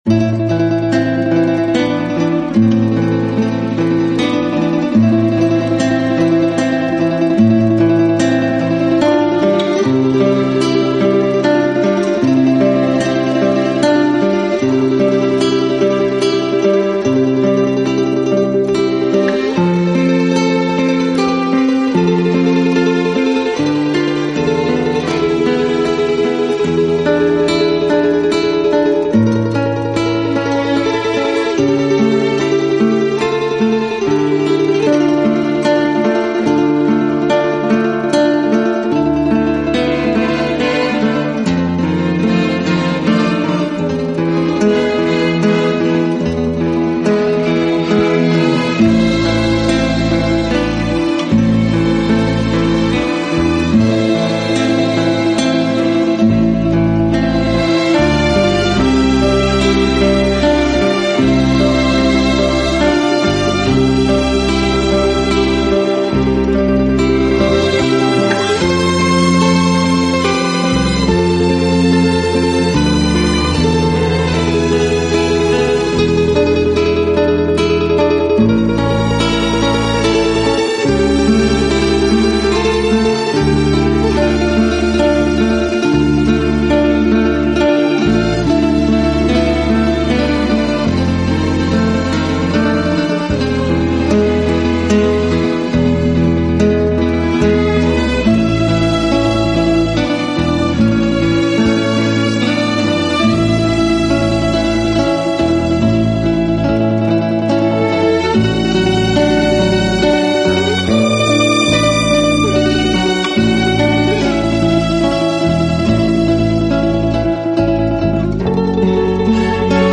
醇和，柔美华丽的音色，颗粒饱满，光辉璀璨的音质，清晰的层次
丰富的和弦，一切你能想到的和希望的精华都在本辑中展露无遗。